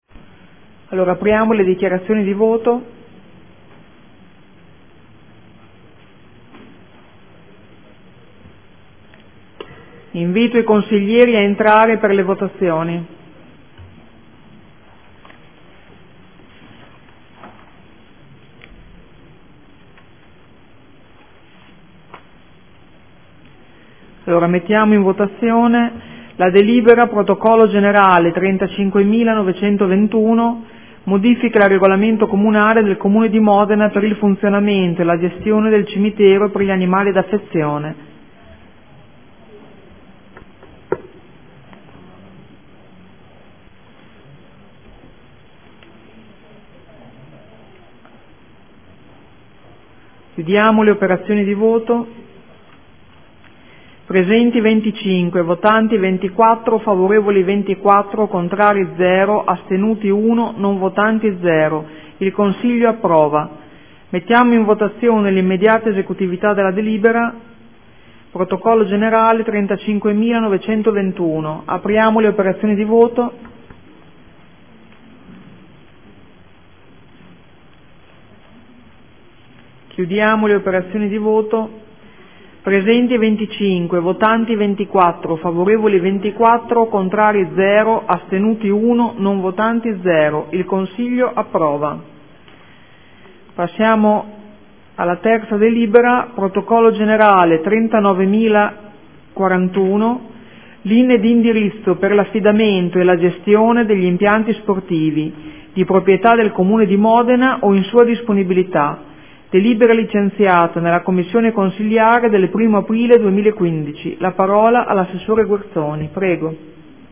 Presidentessa
Audio Consiglio Comunale